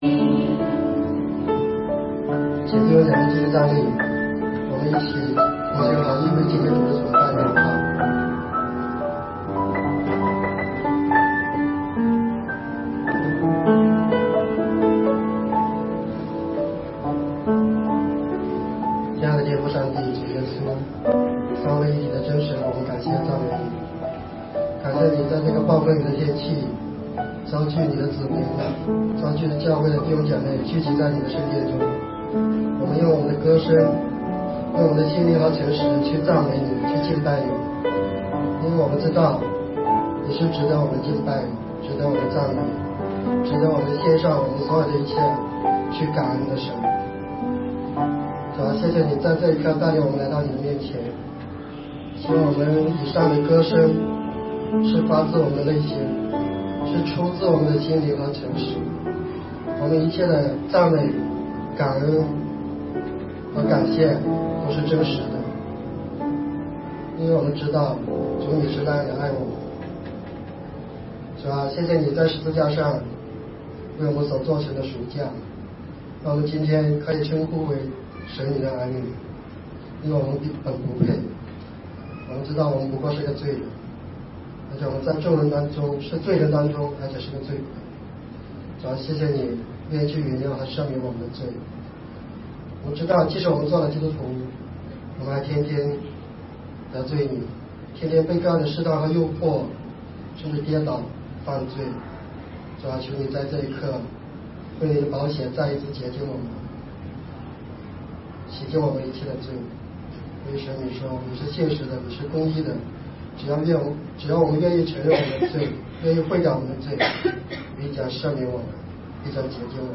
罗马书第24讲 2016年4月10日 下午10:07 作者：admin 分类： 罗马书圣经讲道 阅读(6.39K